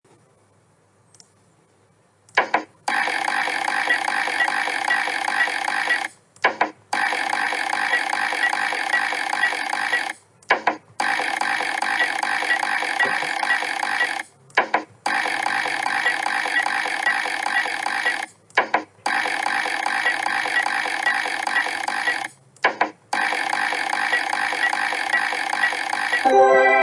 slot-machine-reels-sound-30276.mp3